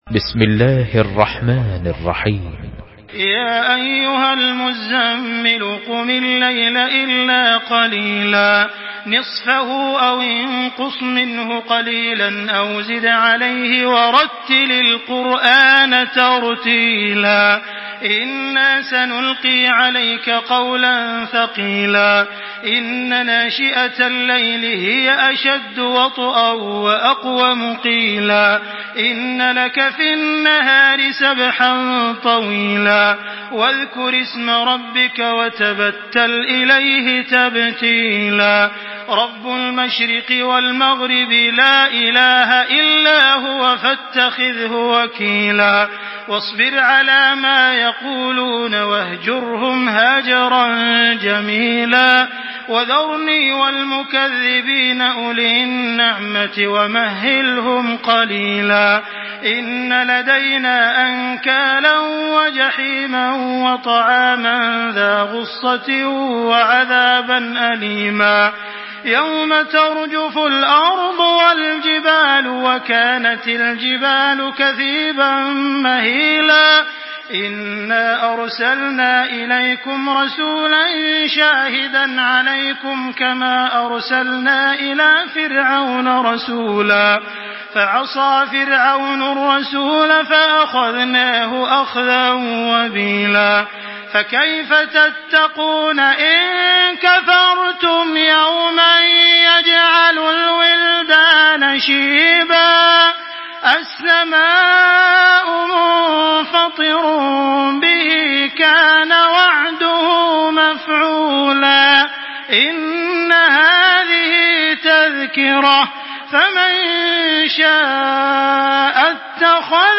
تراويح الحرم المكي 1425
مرتل حفص عن عاصم